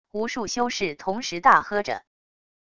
无数修士同时大喝着wav音频